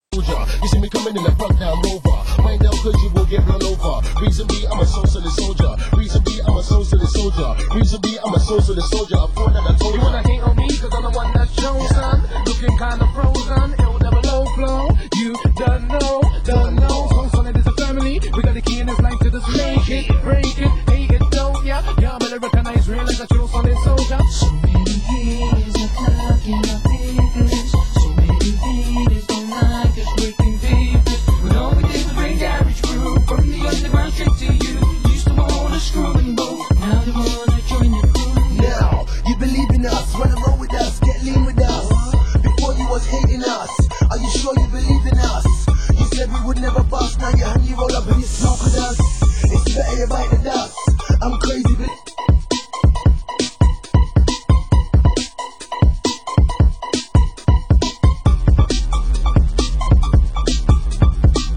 Genre: UK Garage
VOCAL ,INSTRUMENTAL